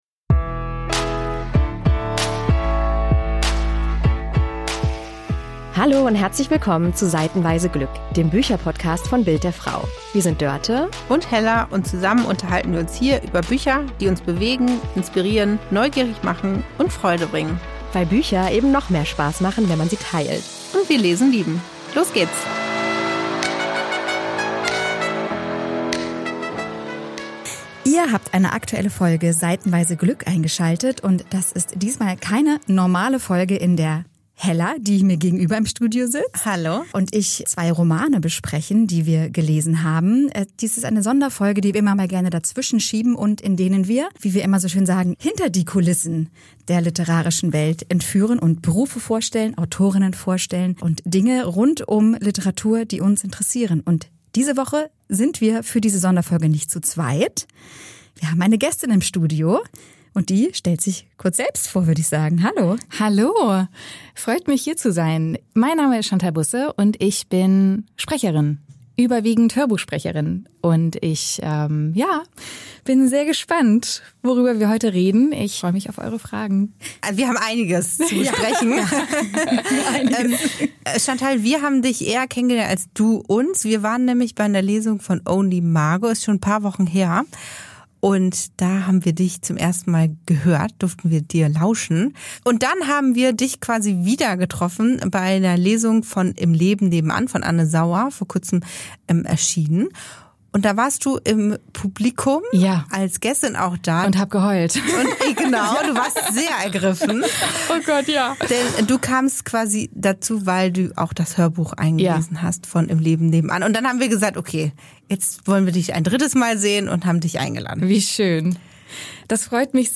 Interview ~ Seitenweise Glück Podcast